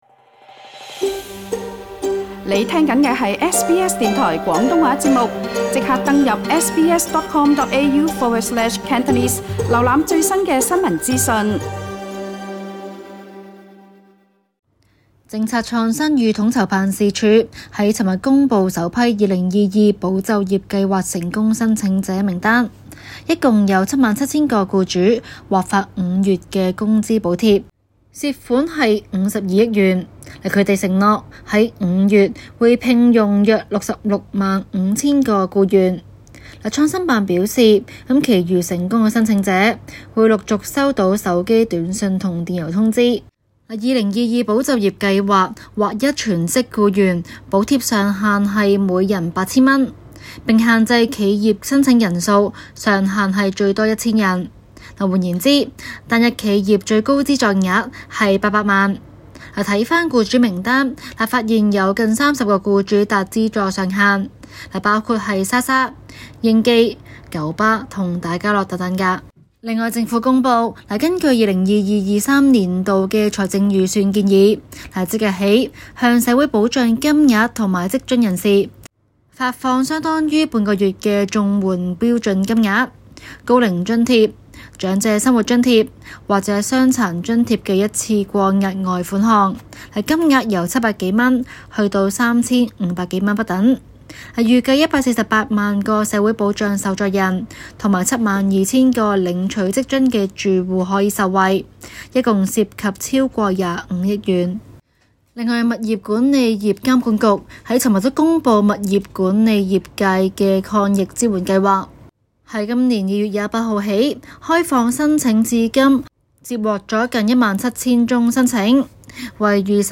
中港快訊